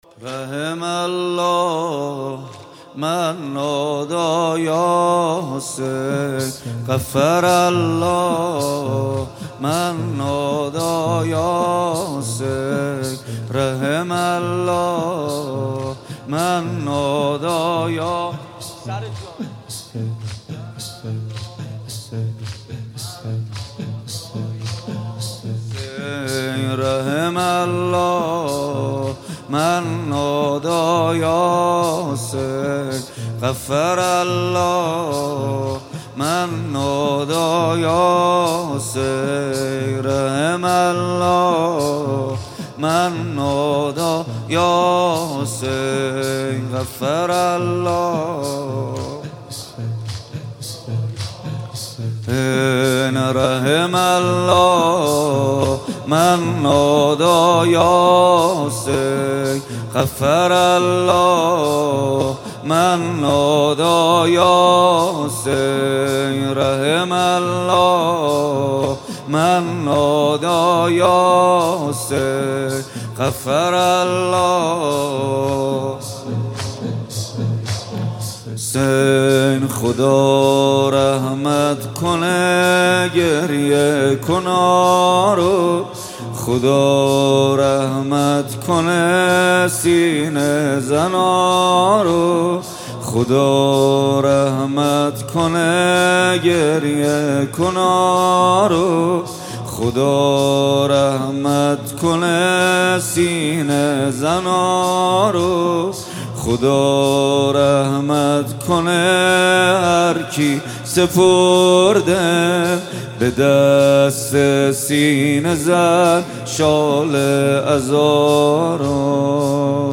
رحم الله من نادی یا حسین مداحی جدید محمد حسین پویانفر شب دوم محرم 1400